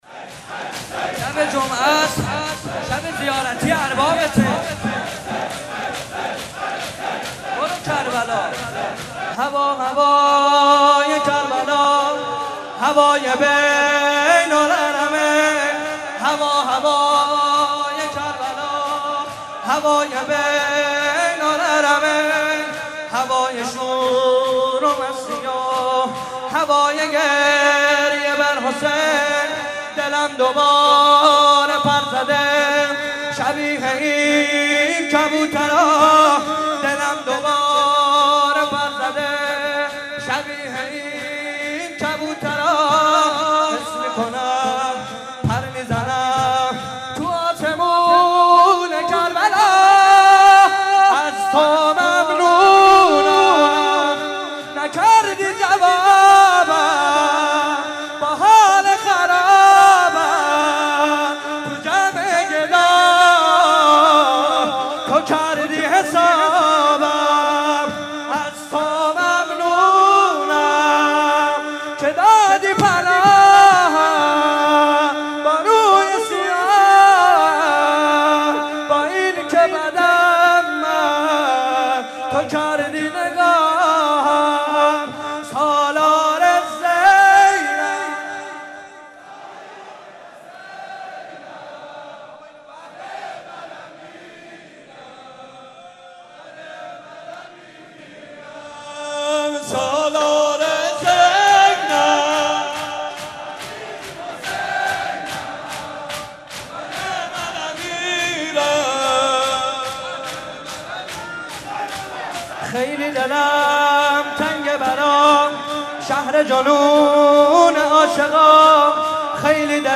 مناسبت : شب بیست و یکم رمضان - شب قدر دوم
مداح : محمدرضا طاهری قالب : شور